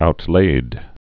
(out-lād)